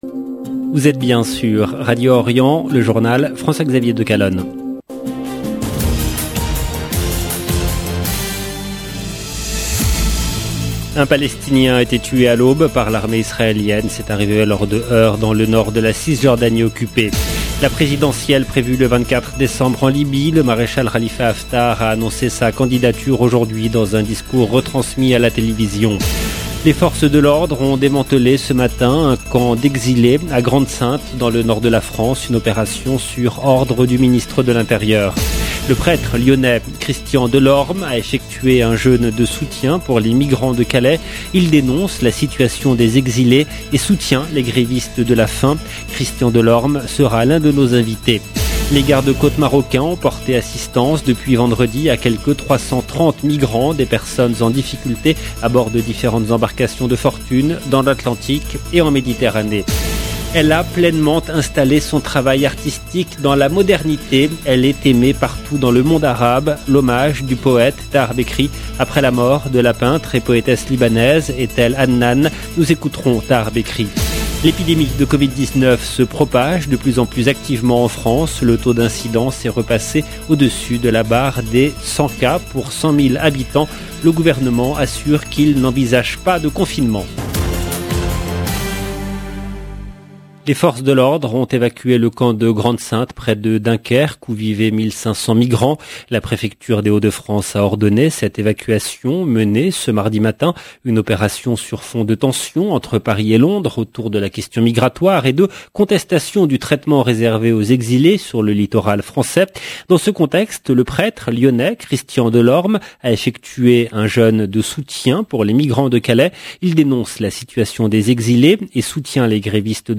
LE JOURNAL DU SOIR EN LANGUE FRANCAISE DU 16/12/21